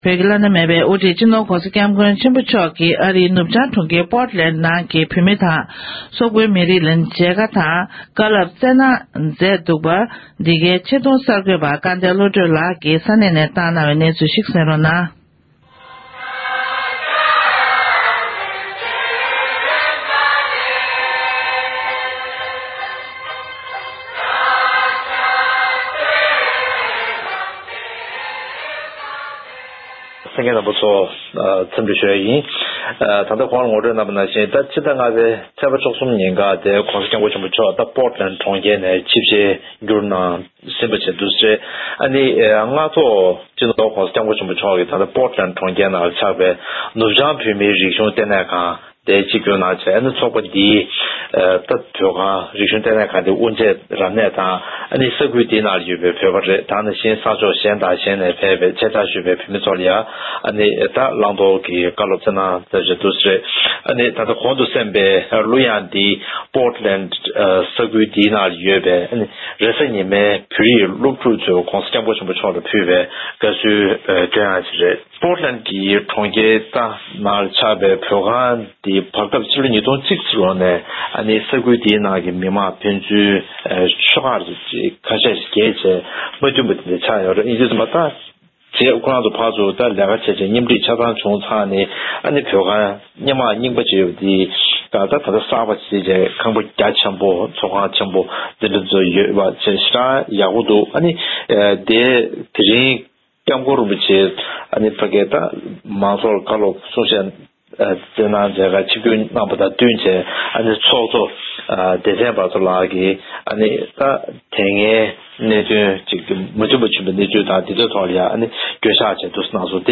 ཆེད་གཏོང་གསར་འགོད་པ